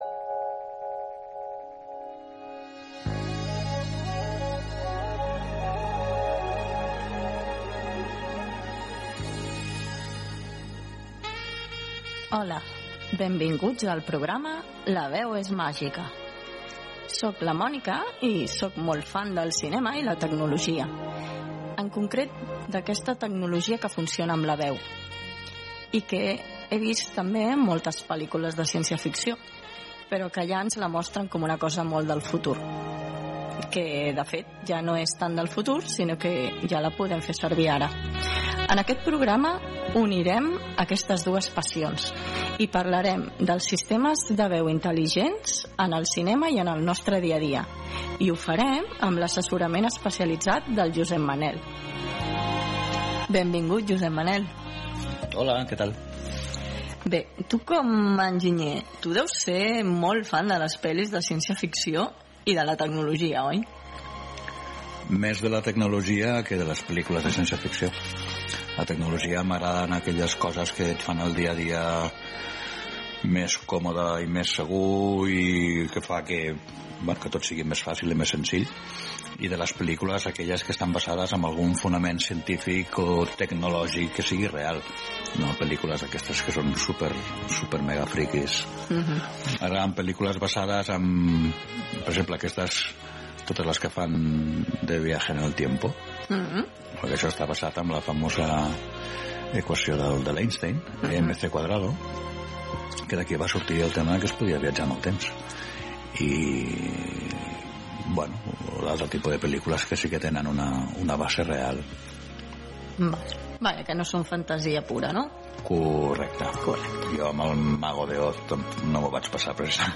Sintonia, presentació del primer programa, explicació sobre la veu sintètica, comentari sobre el cinema de ciència ficció, participació de les assistents de veu Alexa i Siri
Divulgació
FM